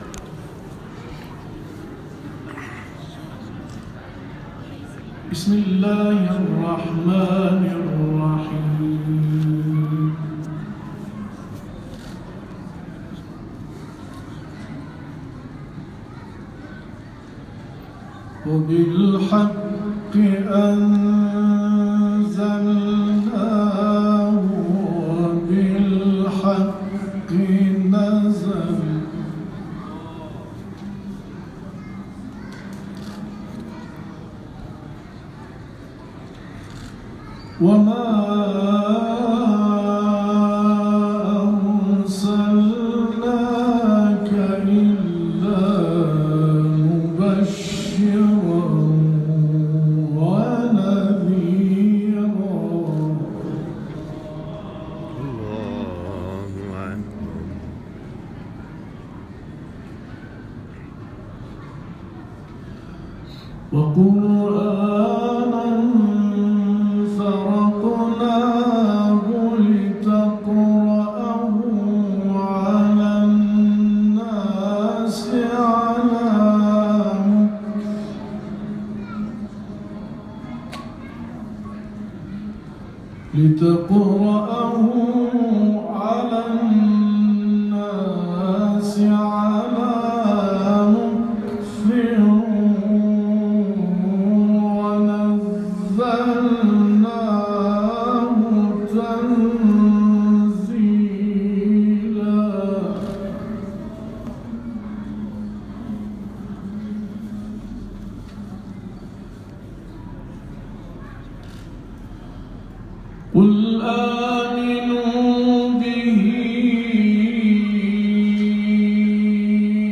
گروه چندرسانه‌ای ــ قراء کاروان قرآنی انقلاب امروز (4بهمن) در چهارمین روز سفر خود از آستان امامزاده هلال‌بن‌علی(ع) در شهر آران و بیگدل گذشتند و آیاتی از کلام الله مجید را تلاوت کردند.